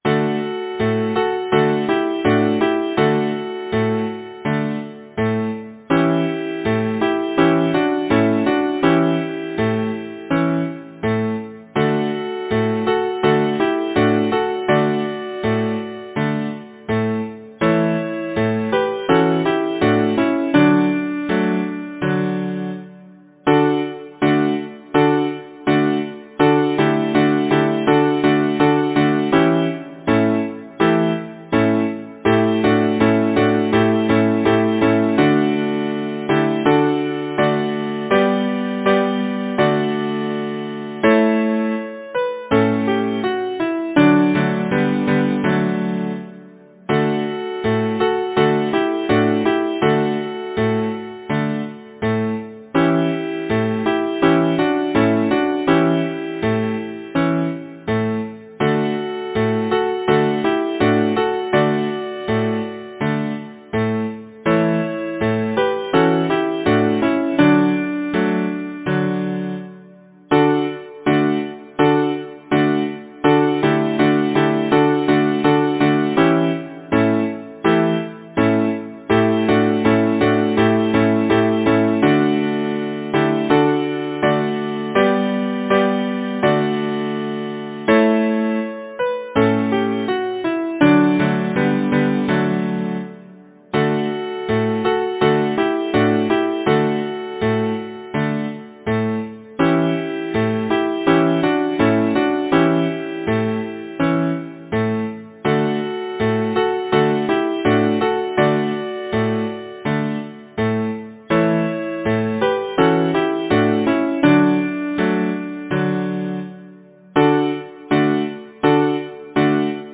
Title: Song of the clock Composer: James Holmes Rosecrans Lyricist: William Henry Gardner Number of voices: 4vv Voicing: SATB Genre: Secular, Partsong
Language: English Instruments: A cappella